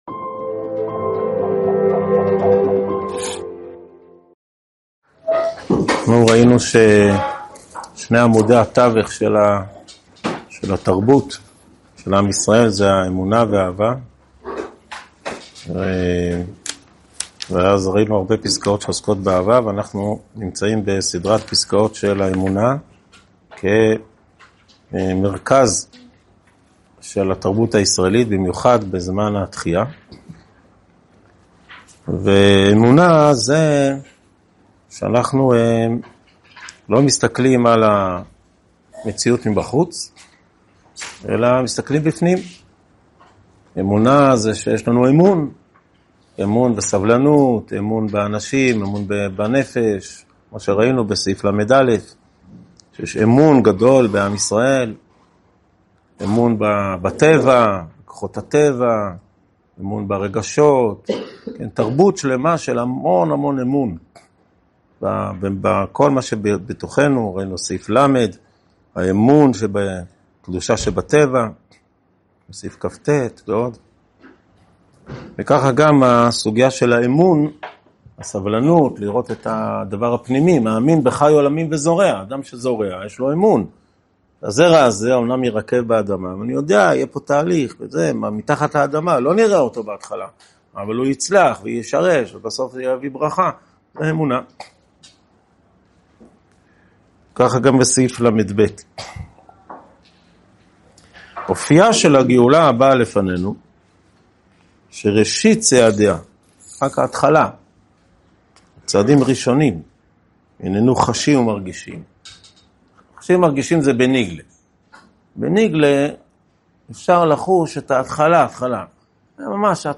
הועבר בישיבת אלון מורה בשנת תשפ"ה.